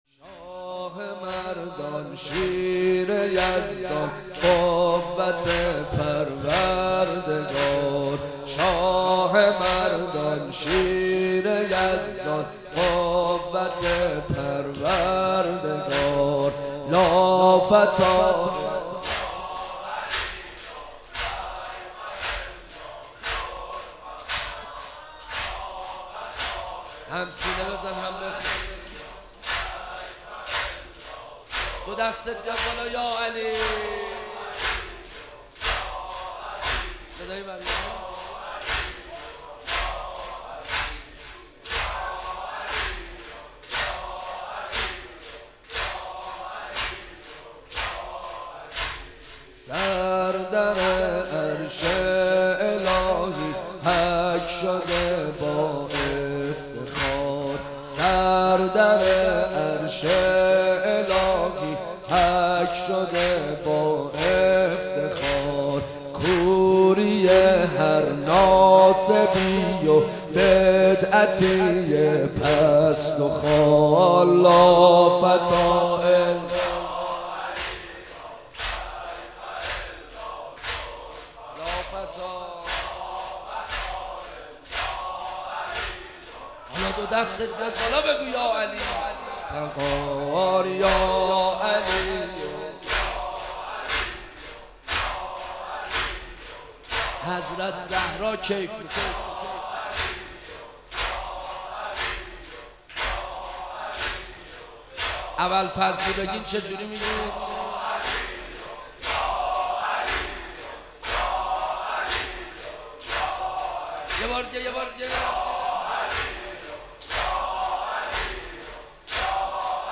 شب هفتم رمضان95
زمینه، روضه، مناجات